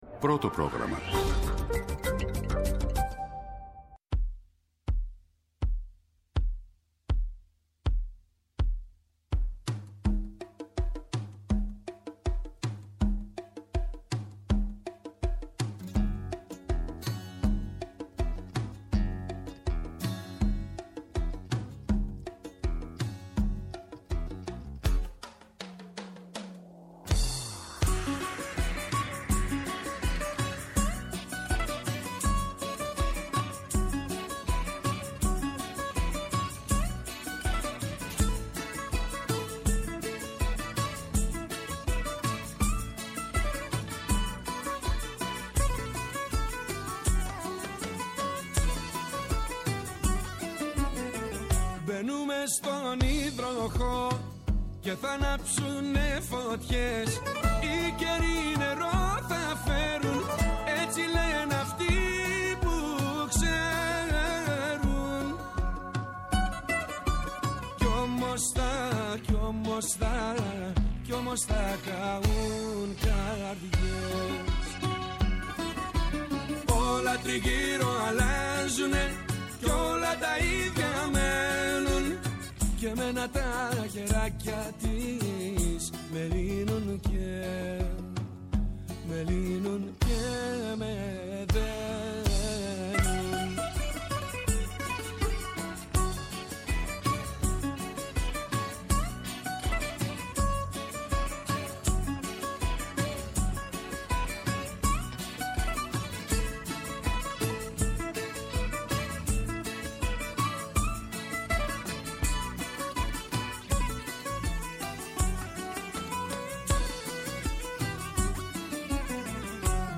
Τα θέματα που μας απασχόλησαν, μέσα από ηχητικά αποσπάσματα, αλλά και συνεντεύξεις.